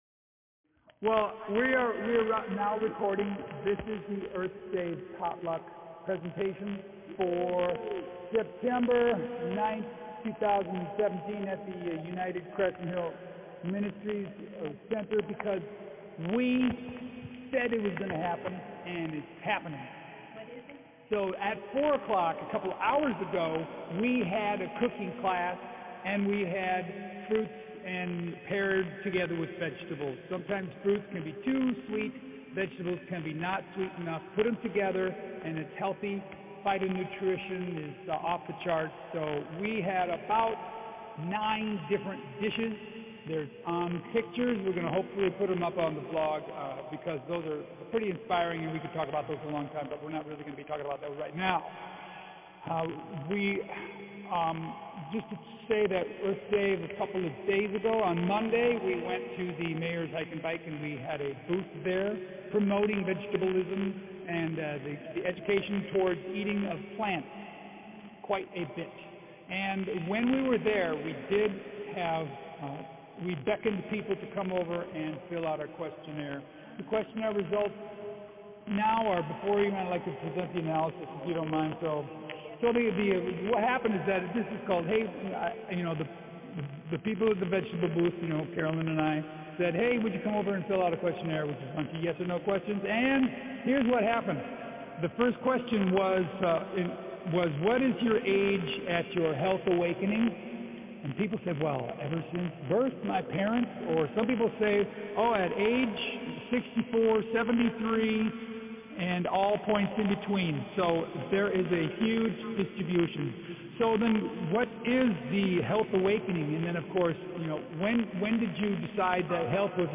Mostly nerdy, silly, and full of well-meaning, innuendo, double ententes, gaps in flow, but some suggestions that any literary critic would praise as worthy of merit.
Here’s the presentation, including both audio and slides.
This is part of the September Earthsave event, found here: